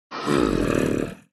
Minecraft Version Minecraft Version latest Latest Release | Latest Snapshot latest / assets / minecraft / sounds / mob / zombie_villager / say1.ogg Compare With Compare With Latest Release | Latest Snapshot